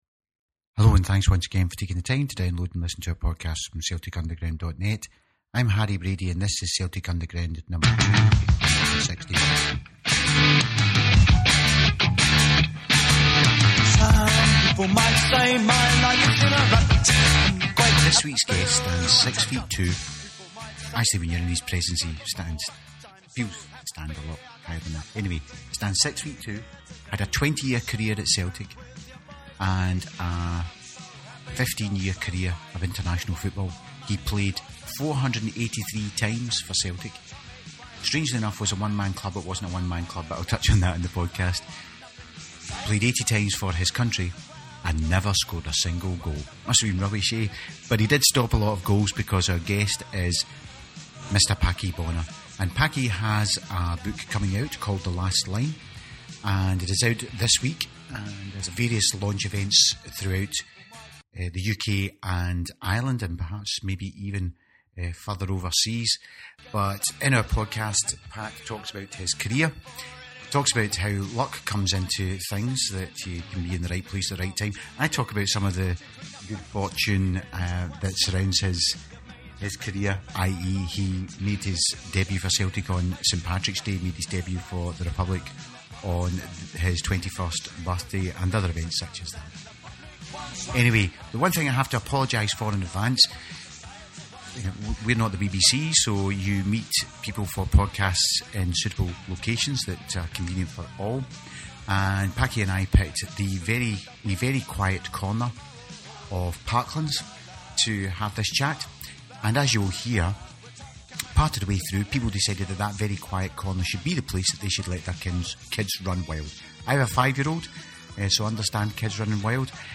The podcast could have covered so much more but as you will hear, from a quiet start the noise from the kids playing nearby meant we eventually had to bring the interview to a close.